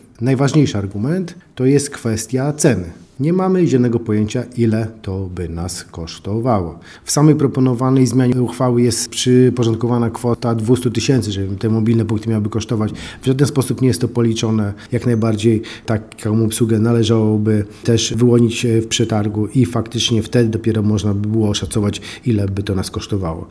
Wiceprezydent Miasta Jerzy Zawodnik podaje szereg powodów odrzucenia wniosku.